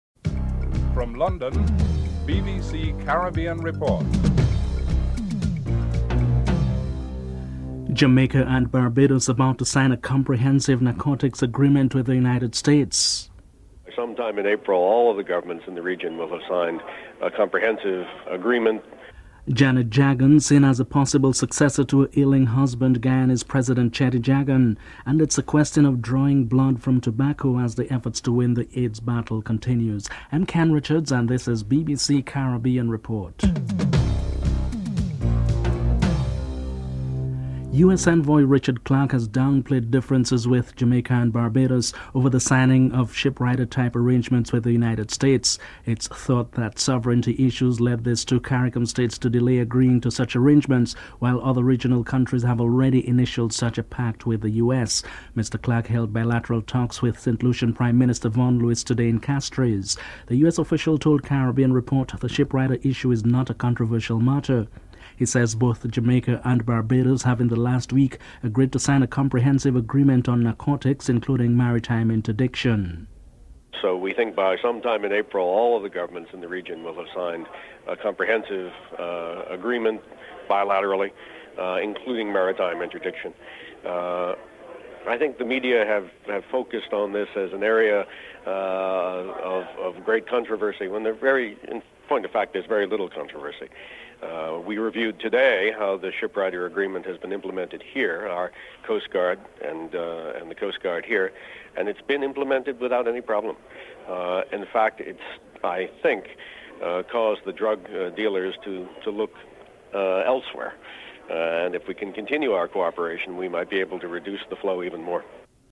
1. Headlines (00:00-00:37)
2. Jamaica and Barbados about to sign a comprehensive narcotics agreement with the United States. United States Envoy, Richard Clarke interviewed (00:38:02:58)